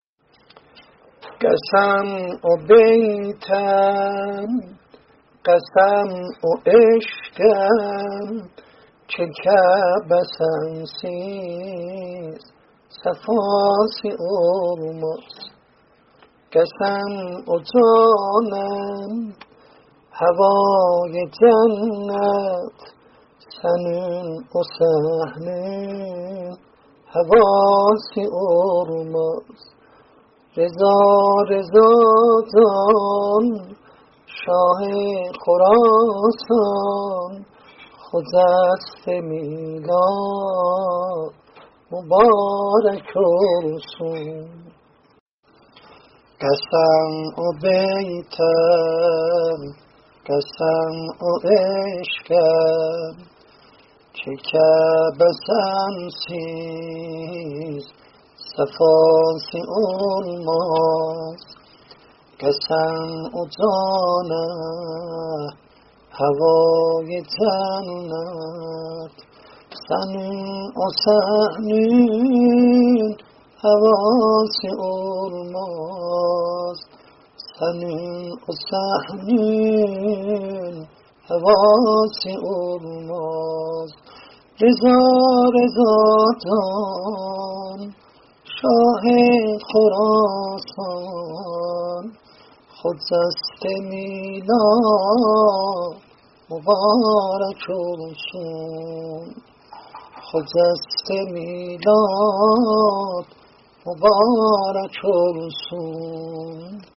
مدح و میلادیه حضرت امام رضا علیه السلام